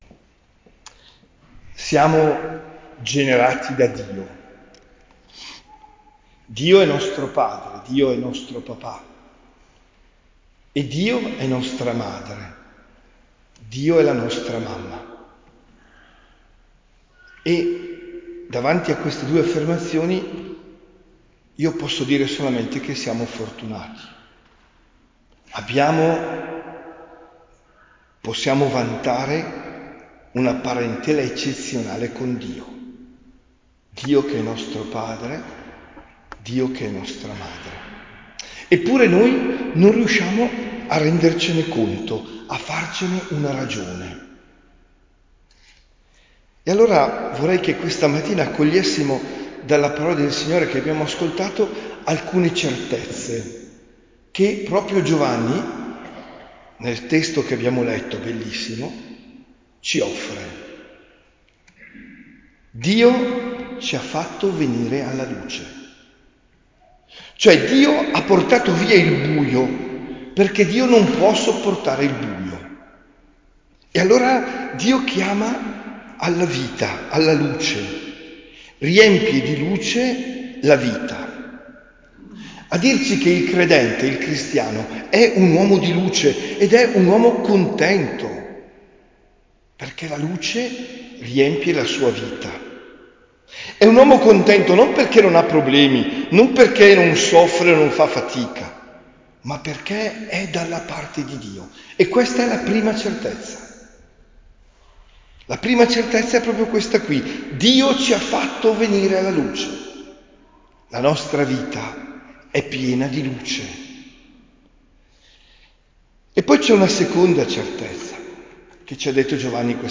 OMELIA DEL 5 GENNAIO 2025